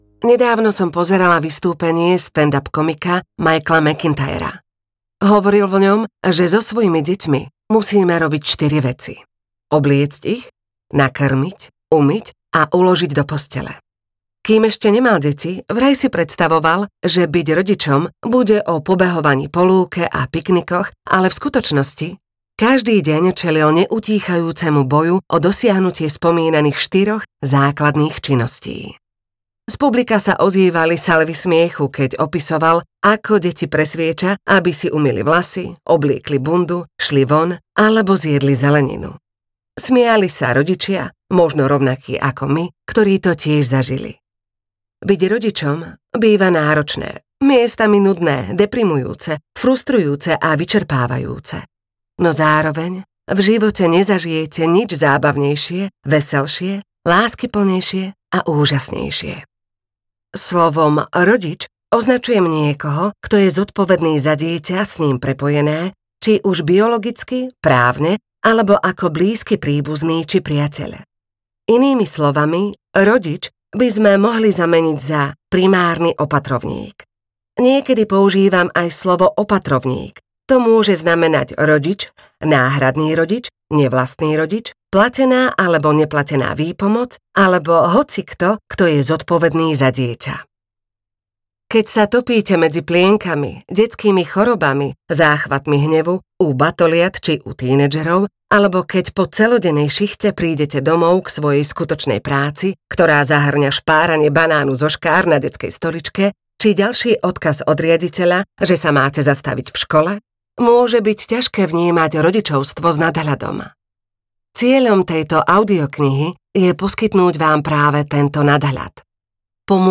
Toto mali čítať naši rodičia audiokniha
Ukázka z knihy